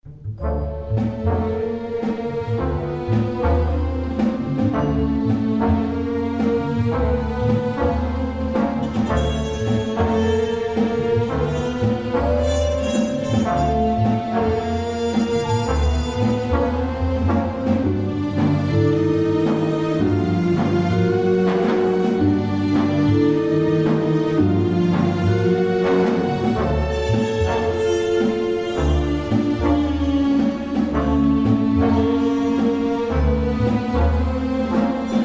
jazz compositions